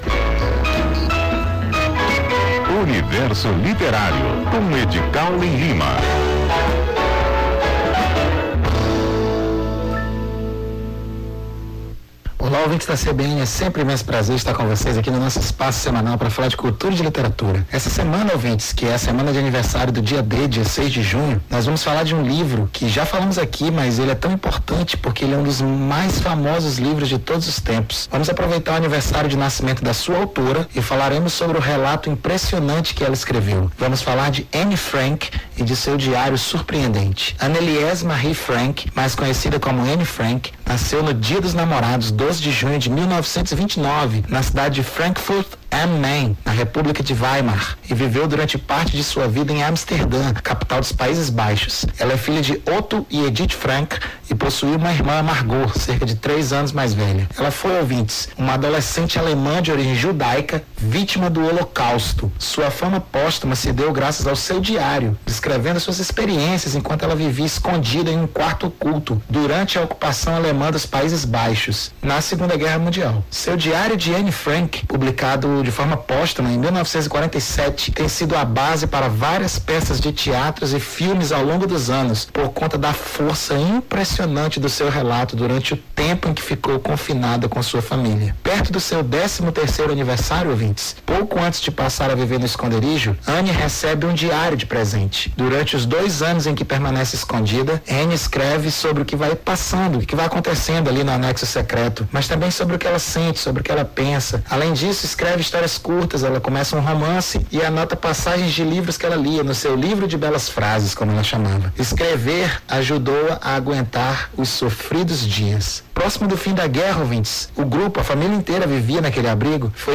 Nome do Artista - CENSURA - ENTREVISTA (UNIVERSO LITERARIO) 06-06-23.mp3